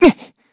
Evan-Vox_Damage_kr_01.wav